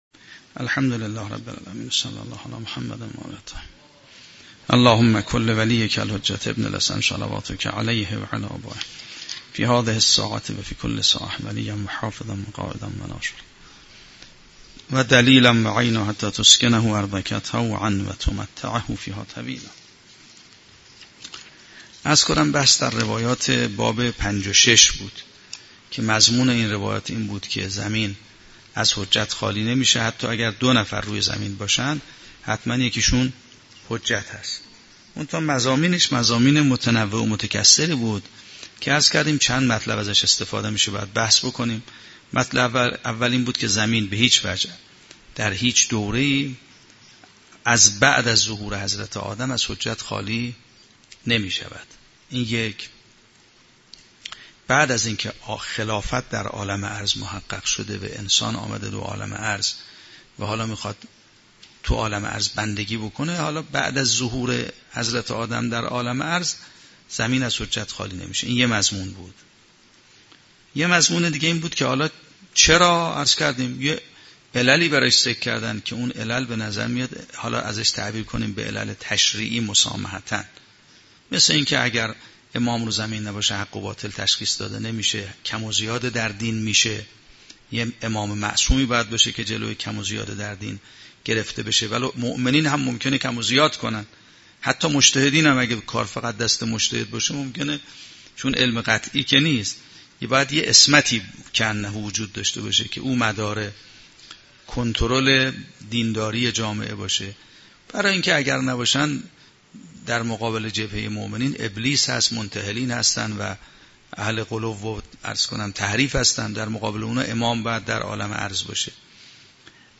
شرح و بررسی کتاب الحجه کافی توسط آیت الله سید محمدمهدی میرباقری به همراه متن سخنرانی ؛ این بخش : تحلیل ضرورت حضور حجت الهی در زمین در مقیاس روایات و ادبیات متکلمین و عرفاء